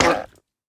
Minecraft Version Minecraft Version snapshot Latest Release | Latest Snapshot snapshot / assets / minecraft / sounds / mob / turtle / hurt5.ogg Compare With Compare With Latest Release | Latest Snapshot
hurt5.ogg